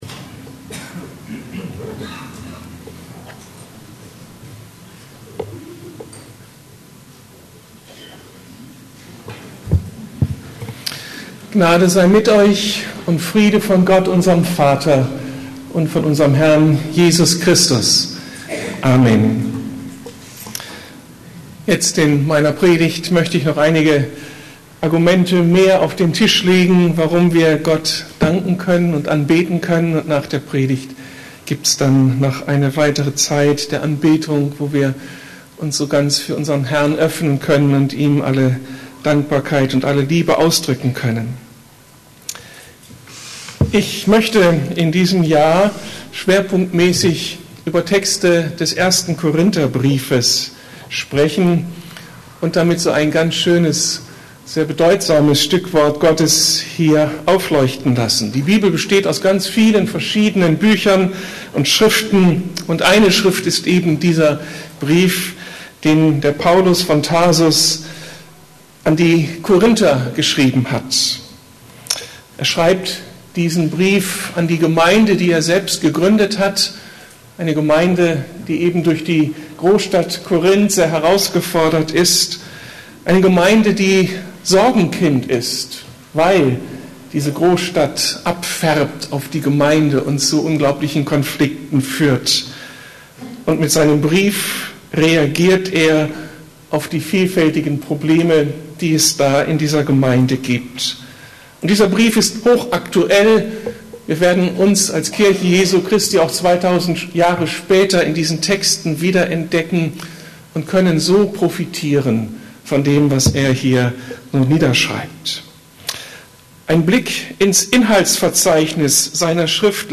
Erneuerung Teil 5: Freiheit von (religiösem) Leistungsdruck ~ Predigten der LUKAS GEMEINDE Podcast